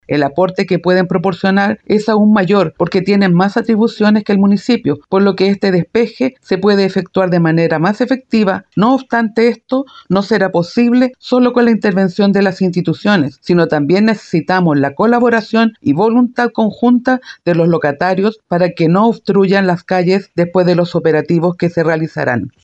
Por otro lado, la edil Carla Sánchez, aseguró que el aporte que pueden realizar tanto la Delegación Presidencial Regional como la Seremi de Salud son aún mayores que los del municipio.